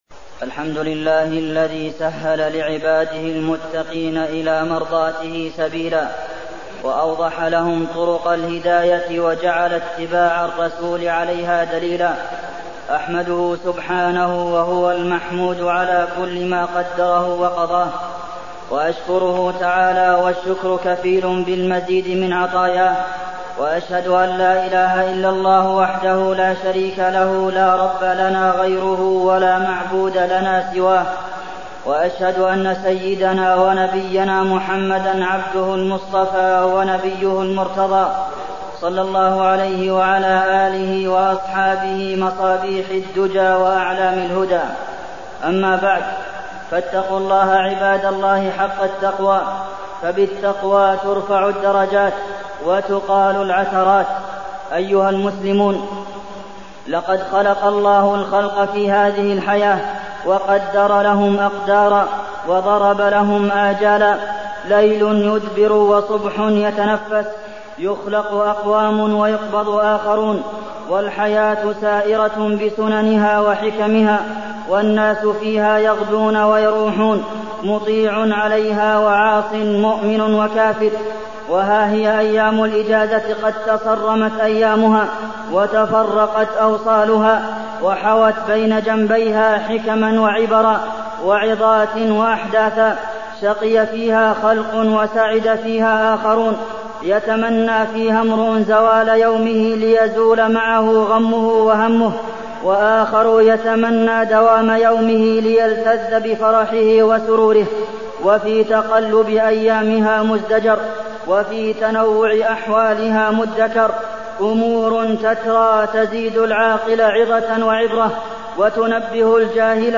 تاريخ النشر ٣٠ جمادى الأولى ١٤٢٠ هـ المكان: المسجد النبوي الشيخ: فضيلة الشيخ د. عبدالمحسن بن محمد القاسم فضيلة الشيخ د. عبدالمحسن بن محمد القاسم محاسبة النفس